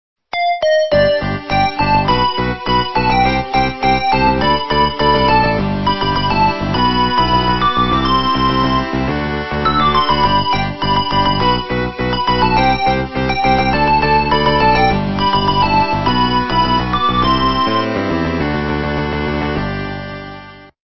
западная эстрада